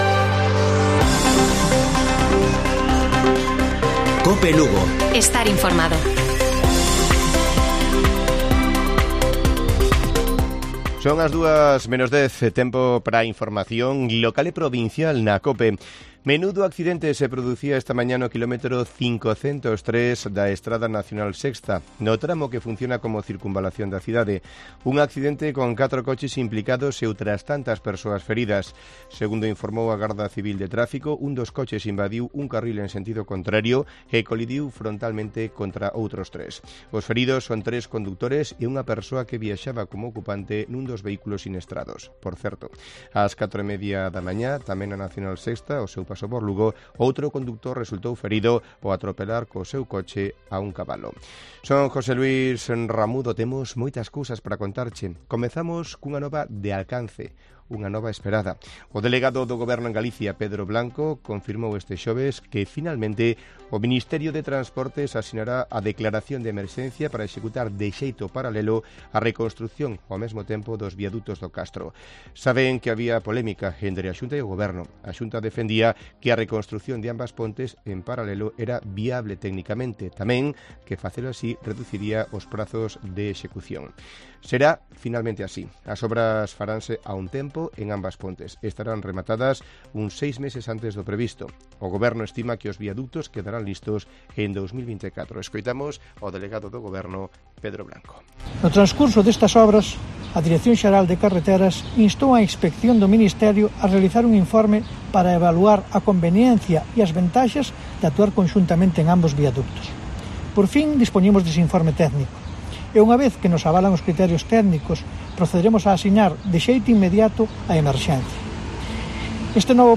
Informativo Mediodía de Cope Lugo. 22 DE JUNIO. 13:50 horas